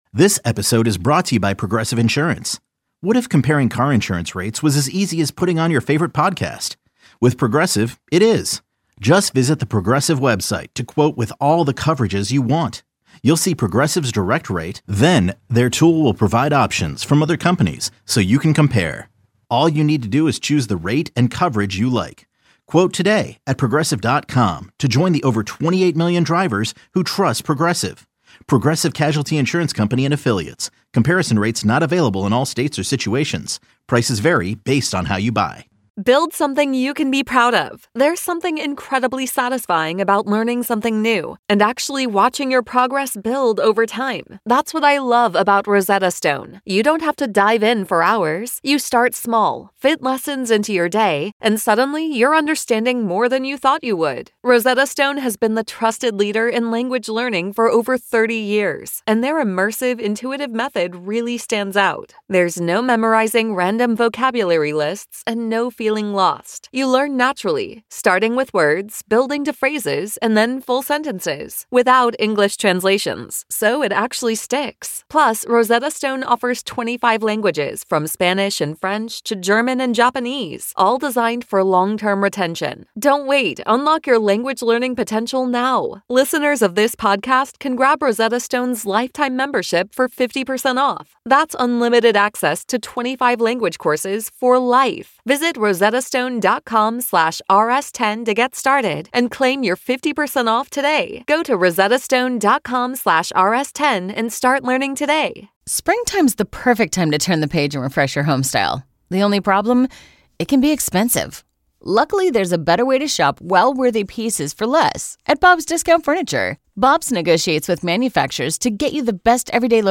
Be a guest on this podcast Language: en Genres: News Contact email: Get it Feed URL: Get it iTunes ID: Get it Get all podcast data Listen Now... DHS launches pilot program with Urban Resource Institute to shelter homeless New Yorkers along with their pets Wednesday